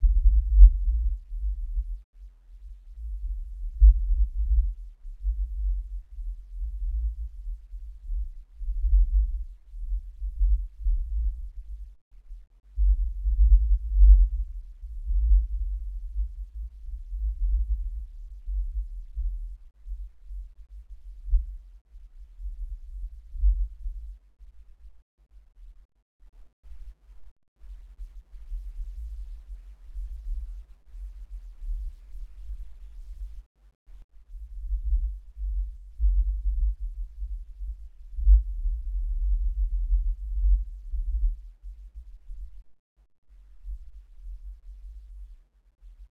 Here are the frequencies removed by the above process. You'll notice a lot of rumble caused by the wind.